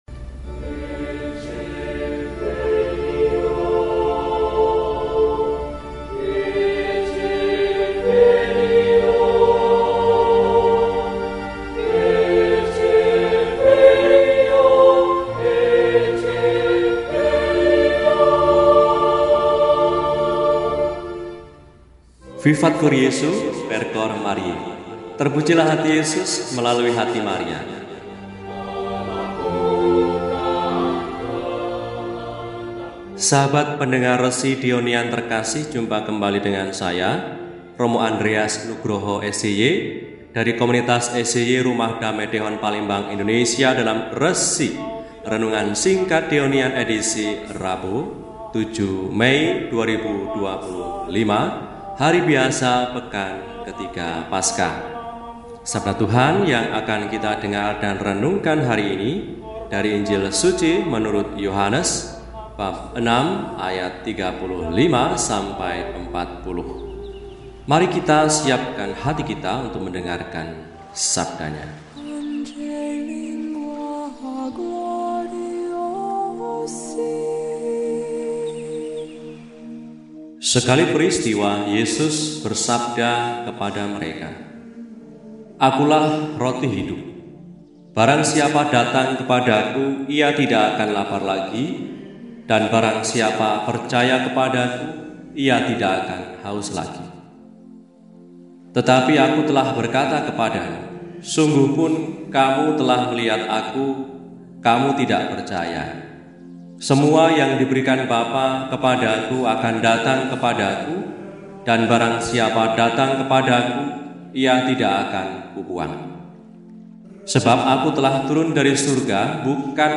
Rabu, 07 Mei 2025 – Hari Biasa Pekan III Paskah – RESI (Renungan Singkat) DEHONIAN